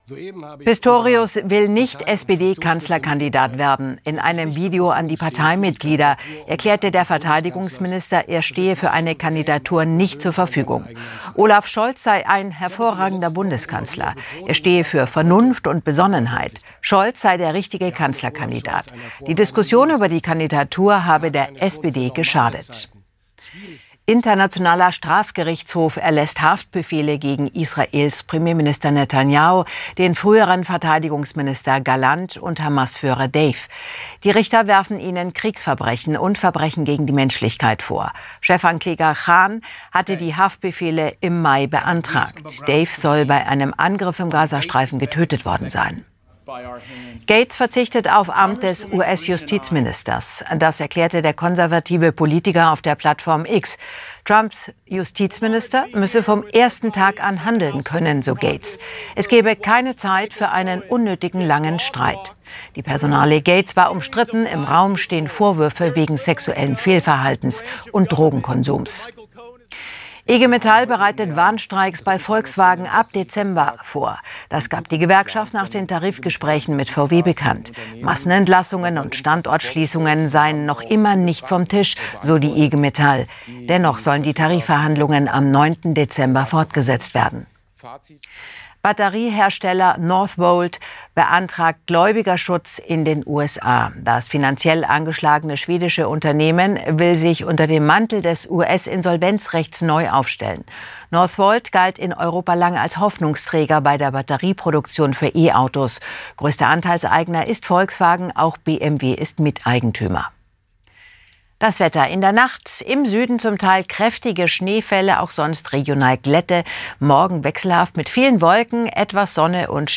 Der tagesschau-Nachrichtenüberblick in 100 Sekunden als Audio-Podcast